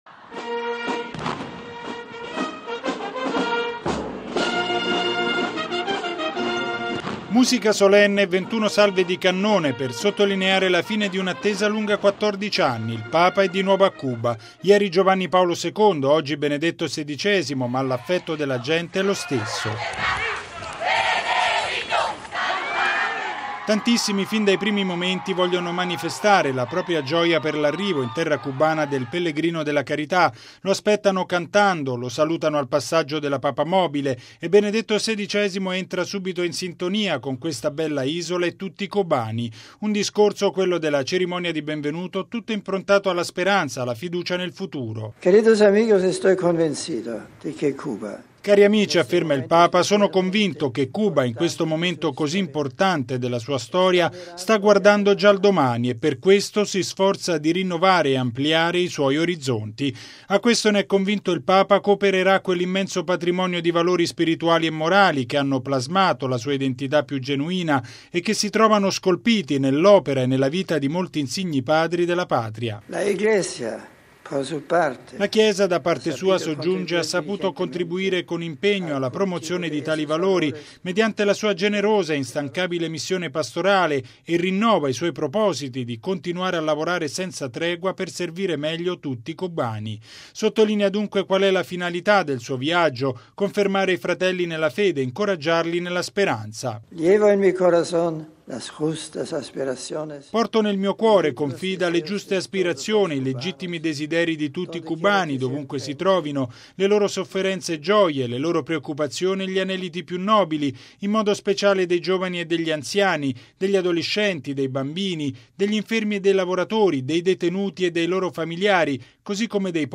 ◊   Primo atto della visita a Cuba è stata la cerimonia di benvenuto all’aeroporto internazionale di Santiago de Cuba, che ha preceduto la grande Messa a “Plaza Antonio Maceo”.